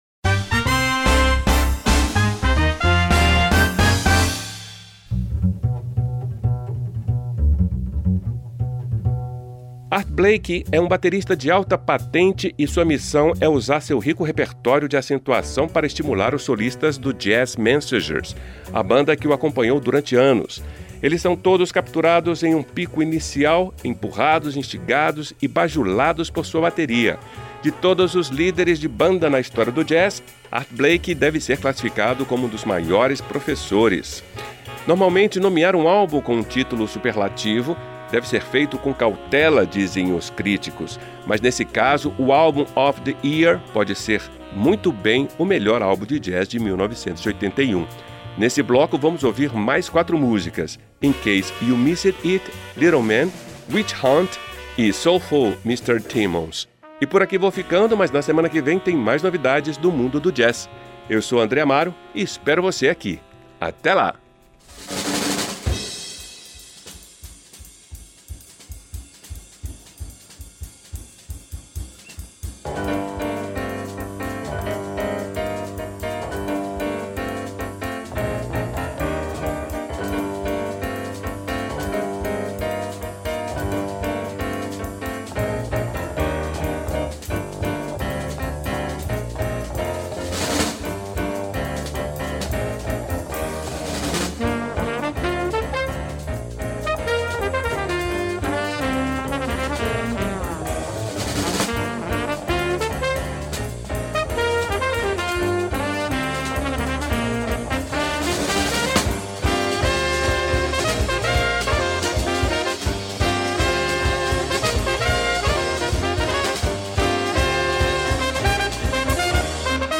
gravado em 1981 em Paris
trompetista
saxofonista
pianista
estilo hard bop